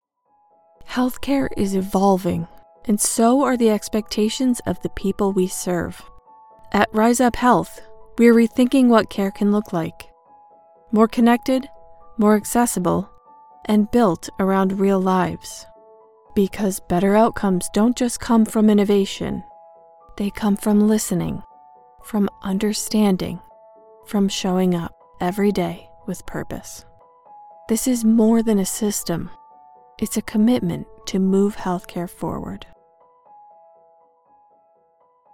Professional Voice, Personality Included - Female Voice actor with chill vibes, a clear voice with adaptability for your project!
Clear compassionate believable Healthcare Brand Internal Video
Middle Aged